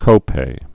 (kōpā)